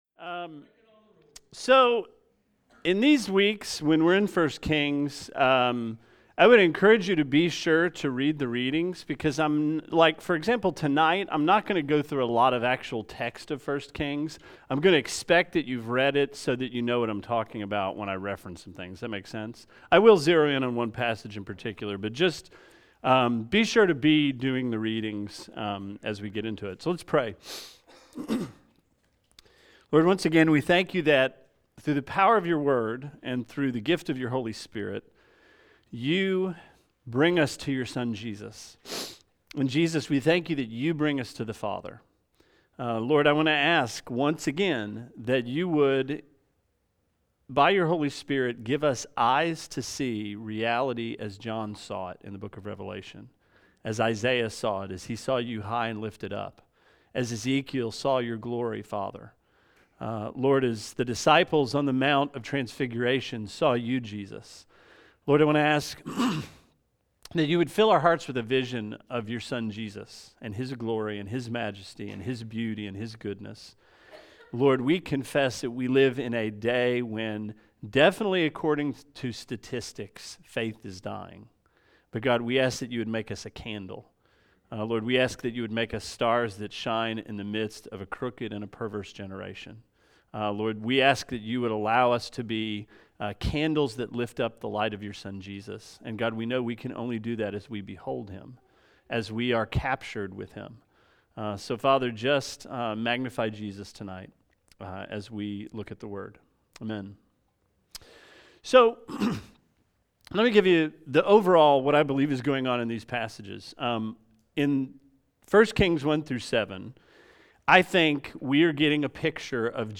Sermon 07/20: 1 Kings 1-7